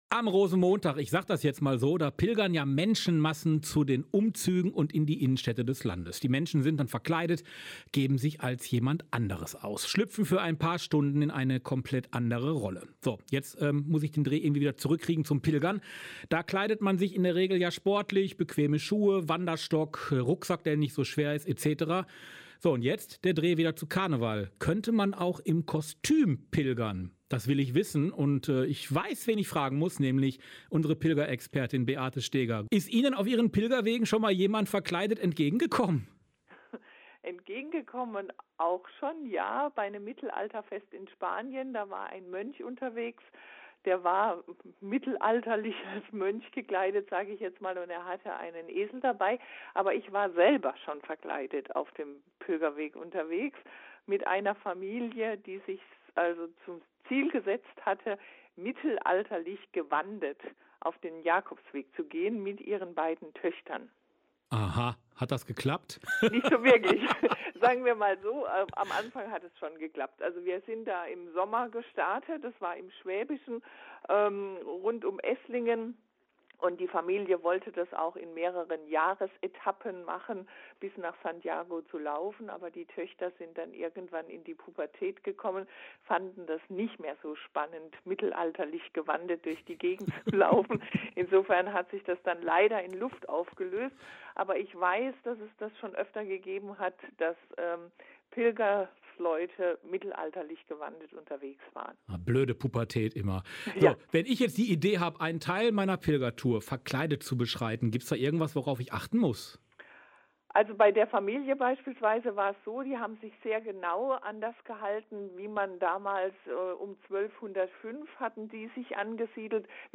Ein Inreview